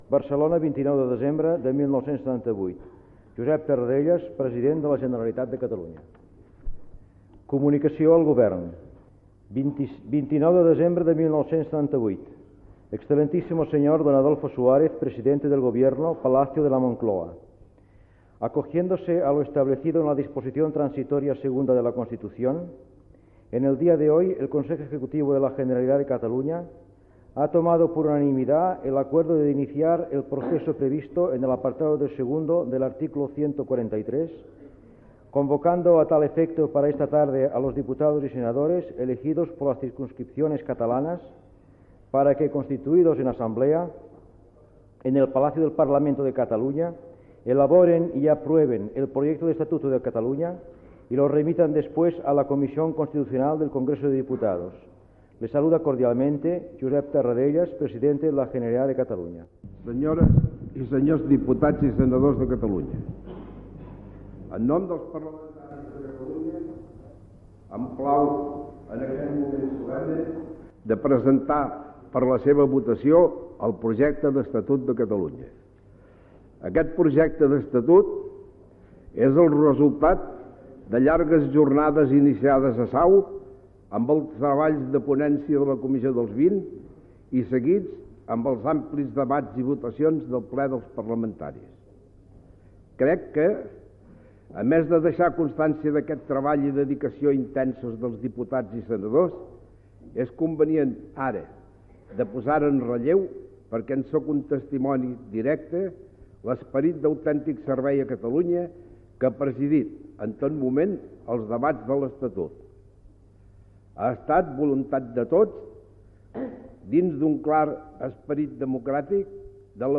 Transmissió des del Parlament de Catalunya de la convocatòria dels diputats i senadors elegits a les circumscripcions electorals de Catalunya, a fi de constituir-se en Assemblea per aprovar el Projecte d'Estatut d'Autonomia de Catalunya
Informatiu
Fragment extret del programa "Com sonava" emès el 19 de novembre de 2016 per Ràdio 4